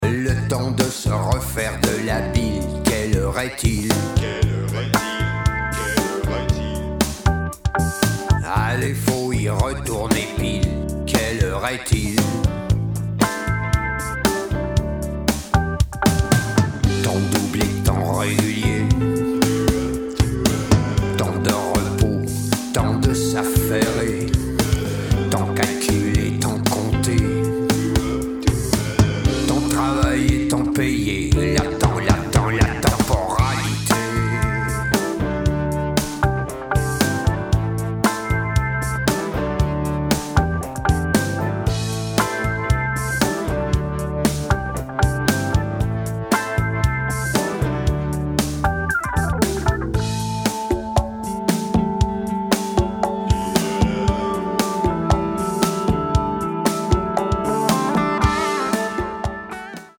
chante et  parle
guitariste
album électrique